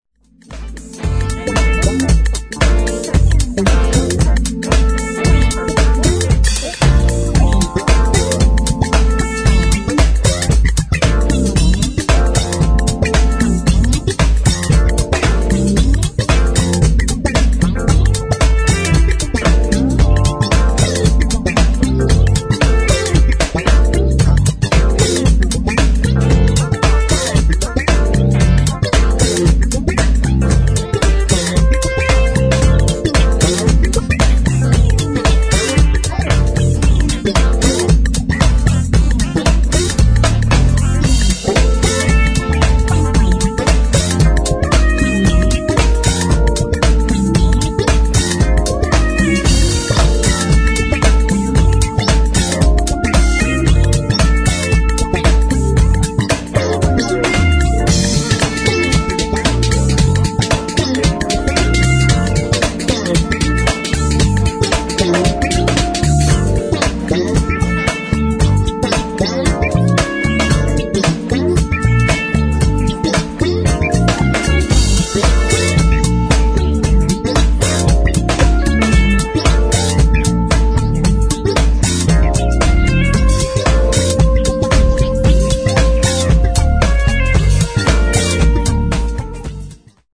[ DISCO / FUNK ]